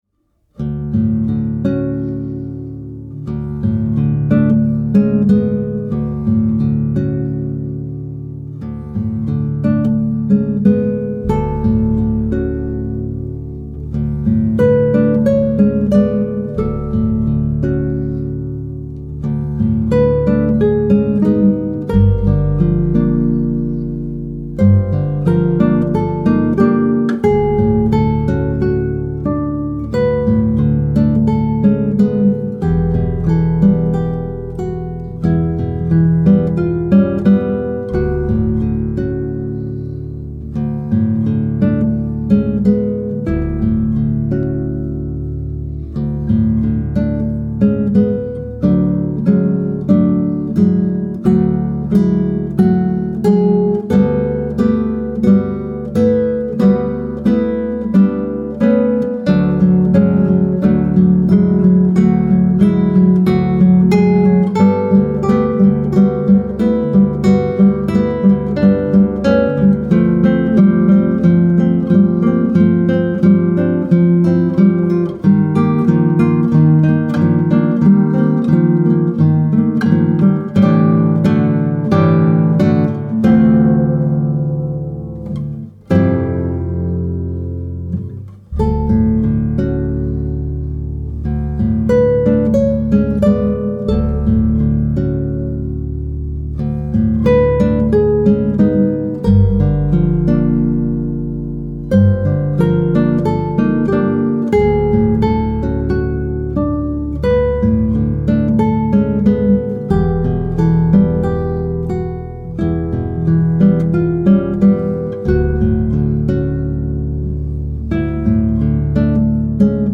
Ruhige, meditative Musik.
Modern Classical Guitar